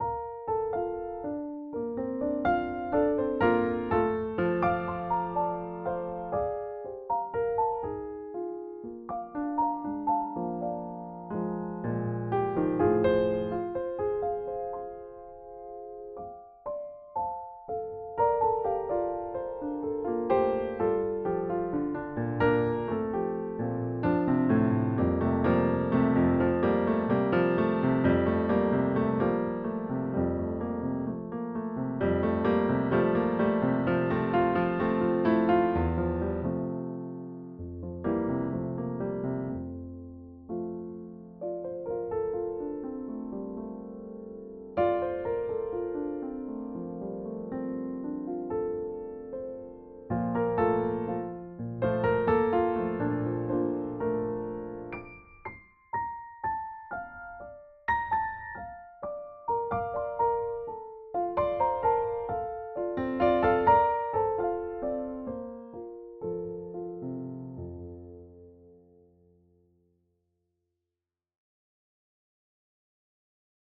Over this week, I’ve been spontanously listening to some piano work, most of them are based on happiness and love.